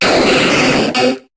Cri de Krabboss dans Pokémon HOME.